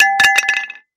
Звуки бейсбола
На этой странице вы найдете подборку звуков бейсбола: от ударов мяча битой до азартных возгласов трибун.